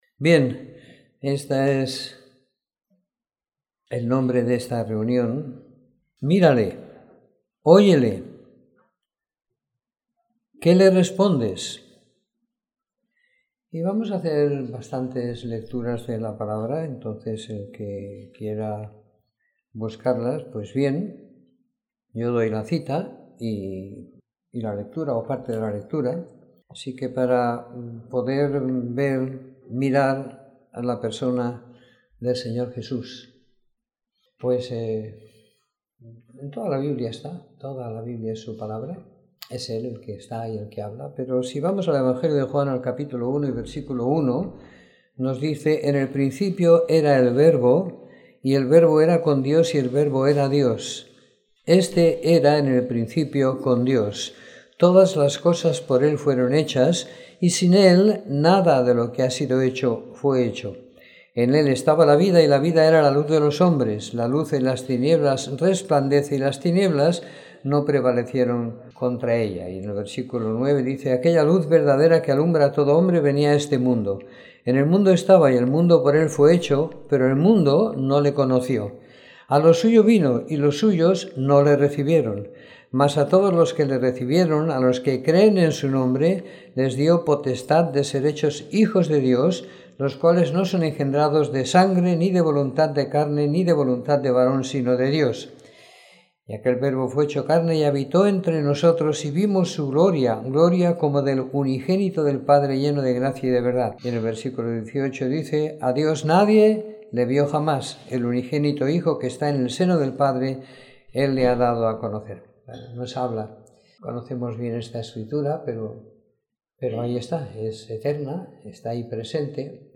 Reunión semanal de Predicación del Evangelio.